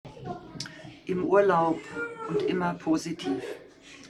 MS Wissenschaft @ Diverse Häfen
Standort war das Wechselnde Häfen in Deutschland.